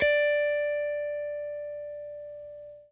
FENDERSFT AO.wav